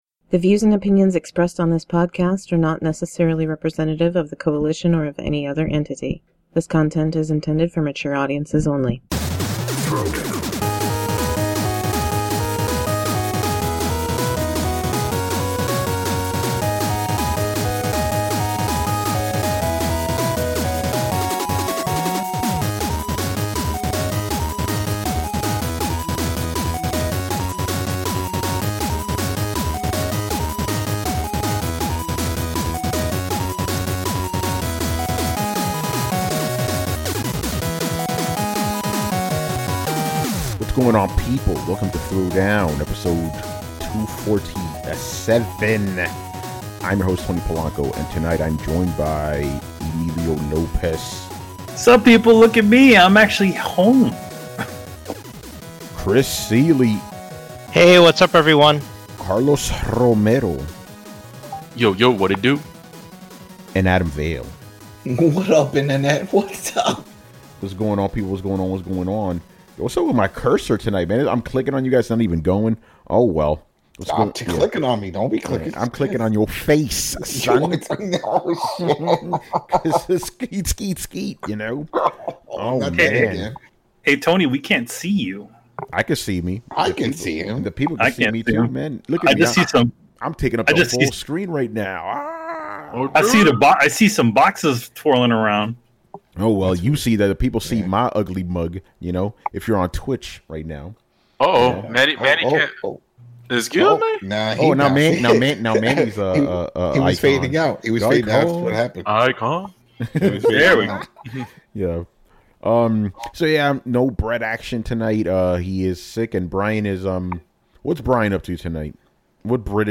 Follow the panelists on Twitter